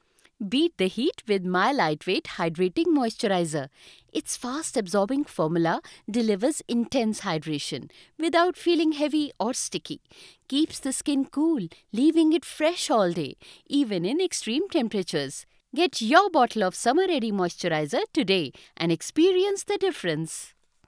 English (Indian)
Conversational Friendly Reliable